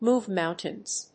アクセントmóve [remóve] móuntains